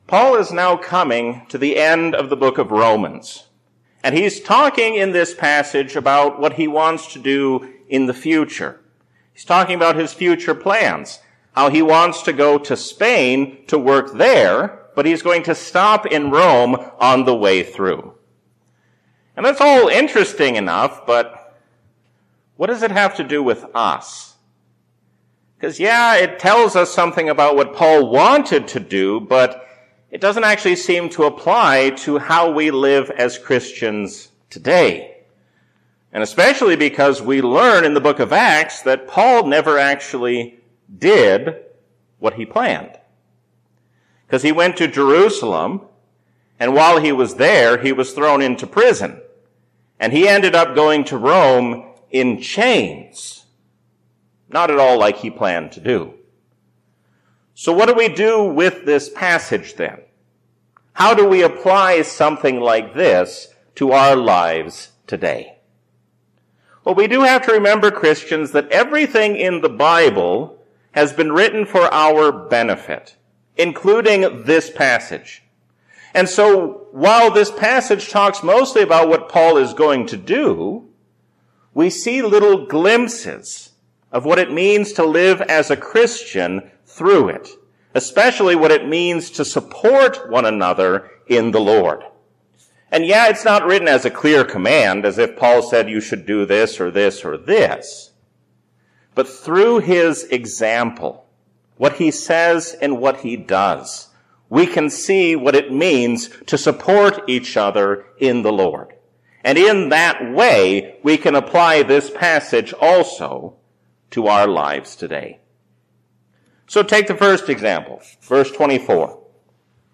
A sermon from the season "Trinity 2022."